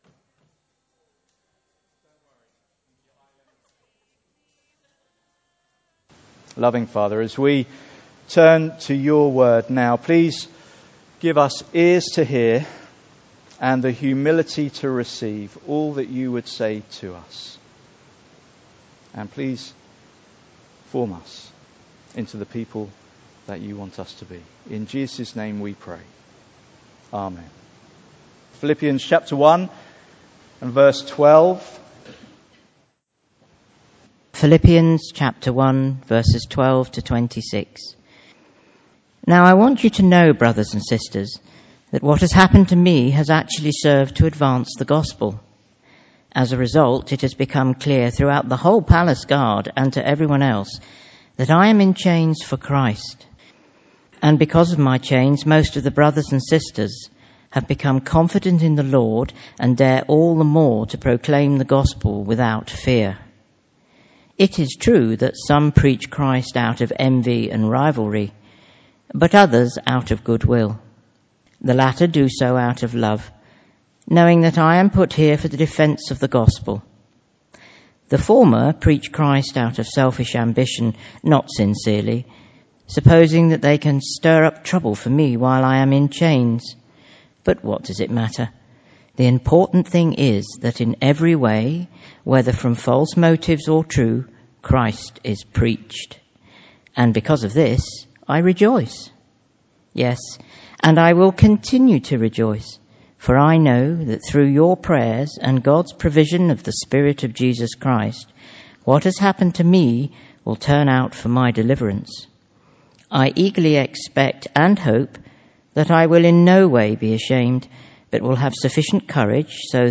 Passage: Philippians 1:12-26 Service Type: Sunday Morning